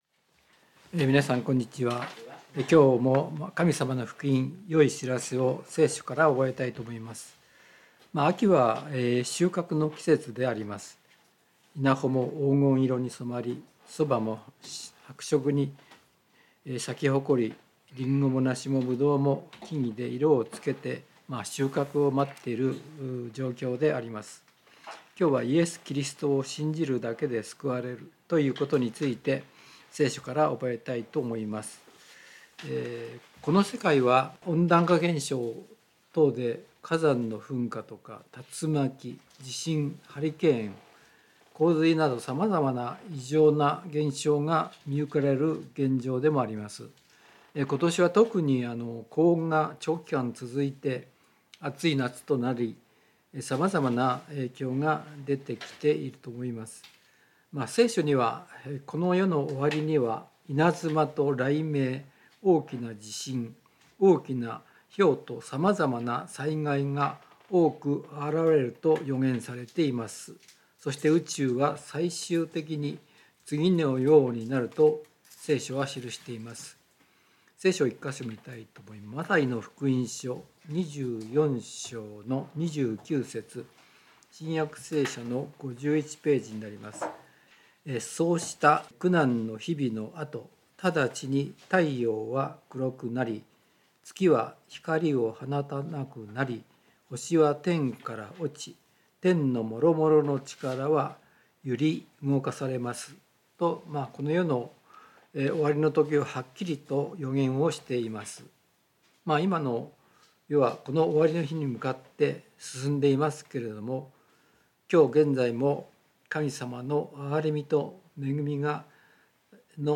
聖書メッセージ No.240